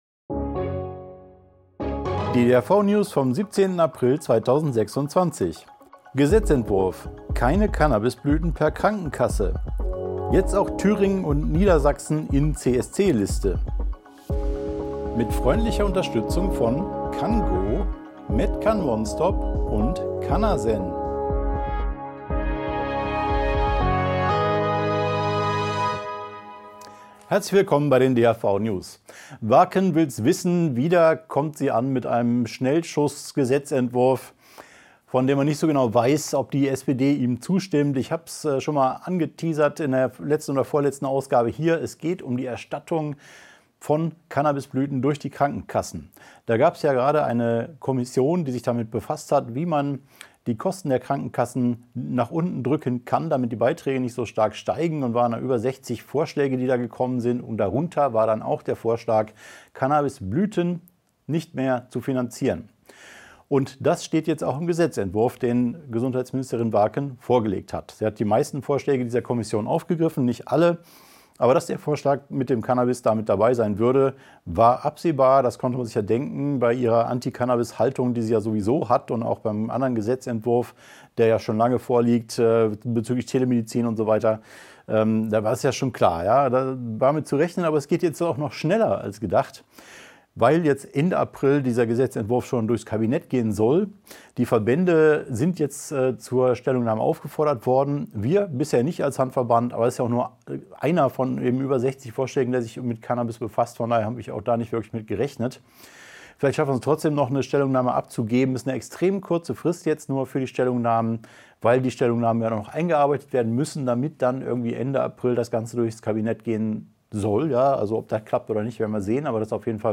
Video-News
DHV-News # 506 Die Hanfverband-Videonews vom 17.04.2026 Die Tonspur der Sendung steht als Audio-Podcast am Ende dieser Nachricht zum downloaden oder direkt hören zur Verfügung.